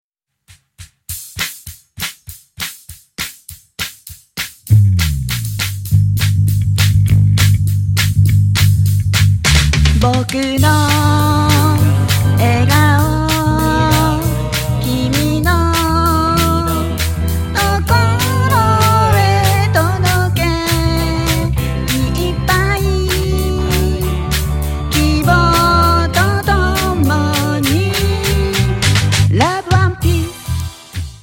新潟長岡アマテラススタジオにて収録